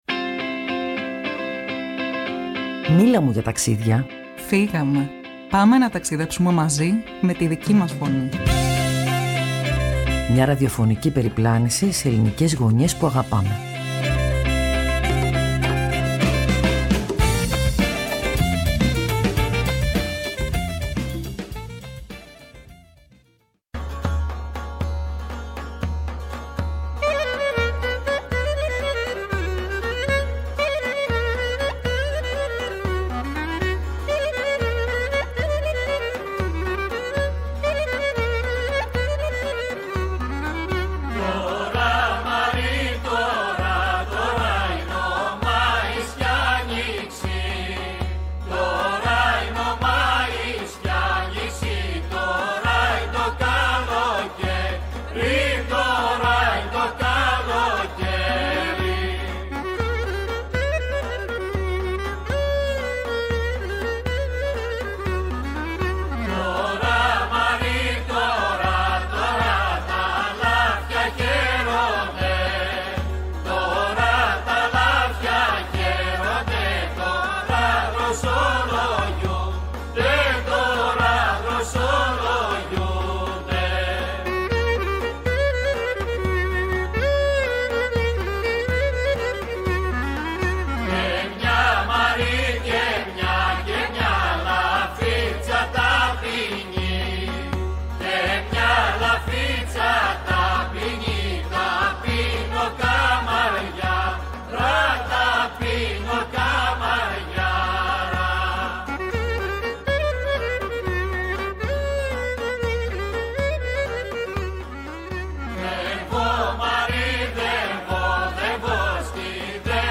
μας ταξίδεψε ζωντανά από το μικρόφωνο της Φωνής της Ελλάδος / ΕΡΤ στο ορεινό χωριό Παύλιανη Φθιώτιδας.